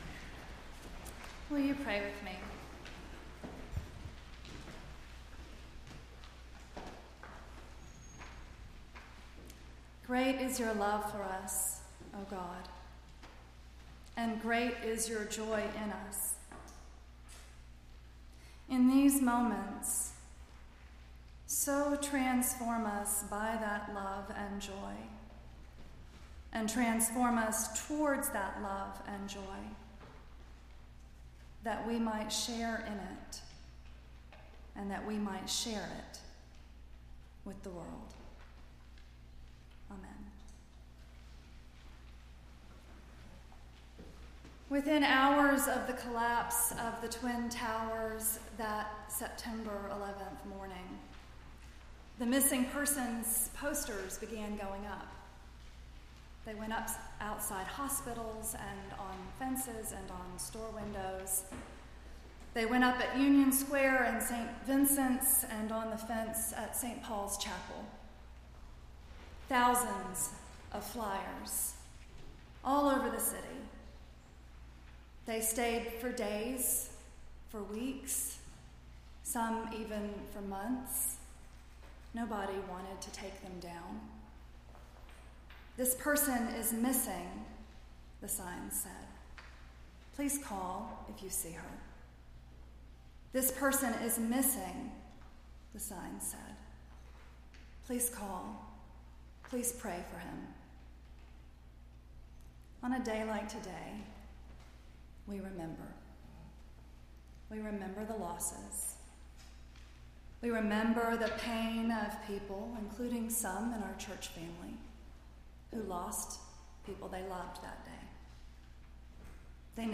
9-11-16-sermon.mp3